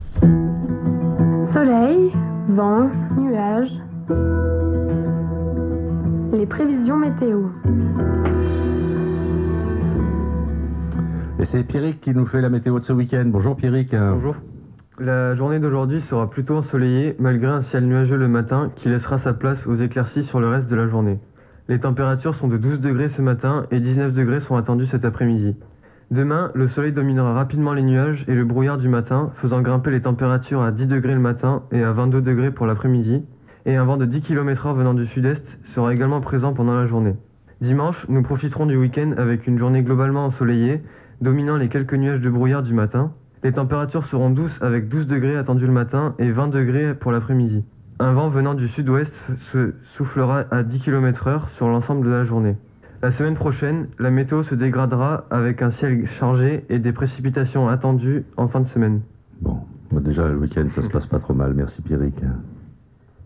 3e bulletin mis en voix